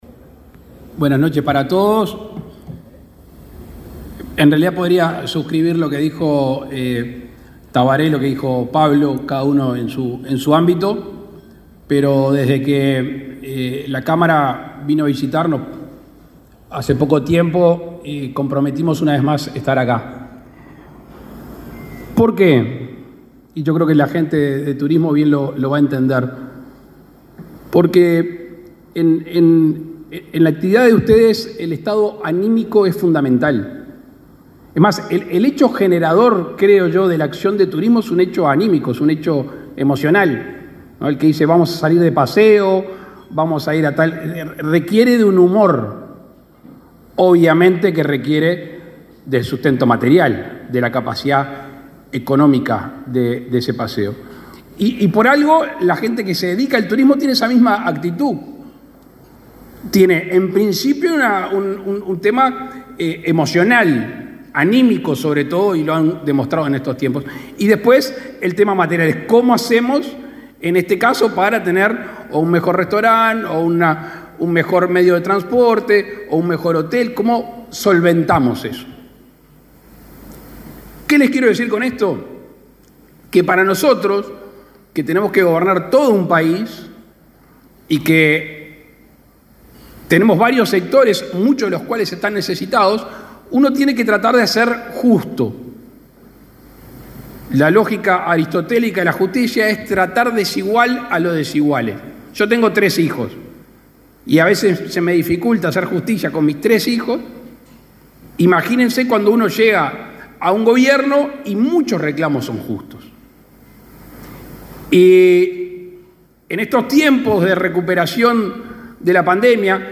Palabras del presidente Luis Lacalle Pou
El presidente Luis Lacalle Pou participó, este martes 26 en la chacra La Redención, de la celebración del Día Mundial del Turismo.